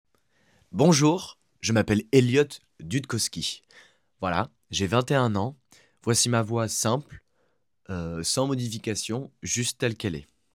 Présentation de ma voix